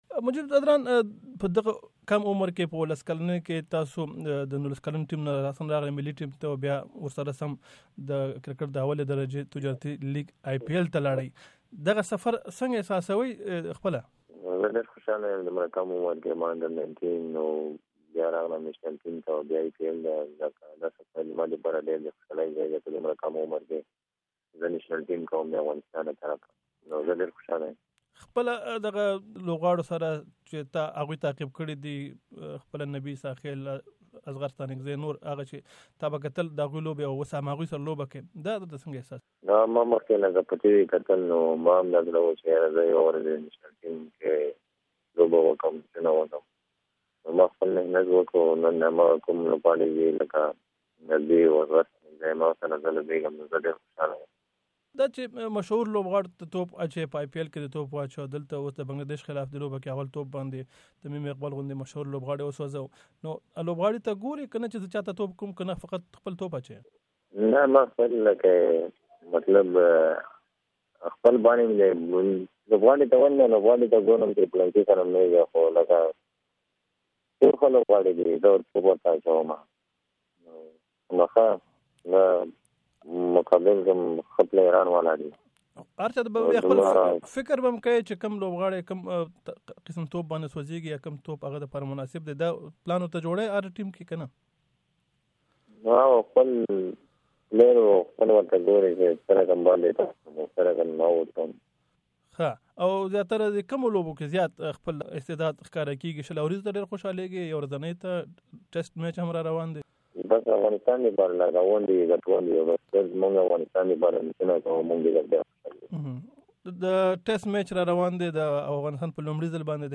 د افغانستان د کرکټ د ملي ټیم ځوان لوبغاړي مجیب ځدران تازه مرکه